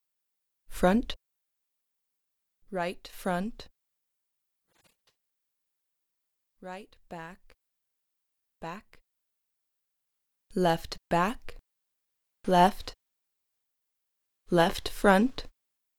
horizontal_test_ambiX.wav